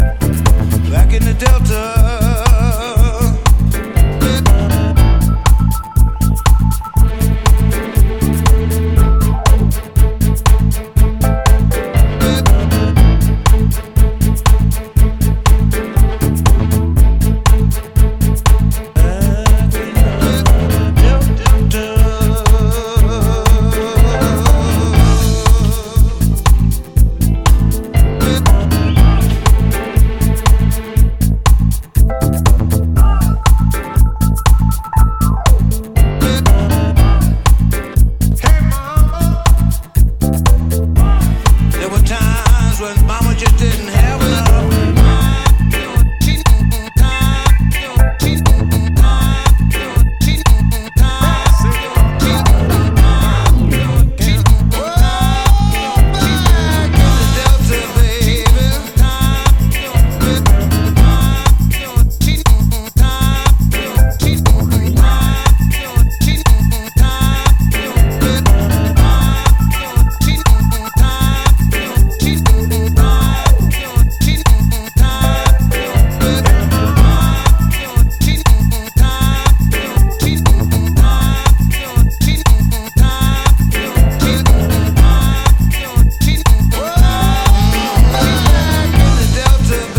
ジャンル(スタイル) DEEP HOUSE / DISCO HOUSE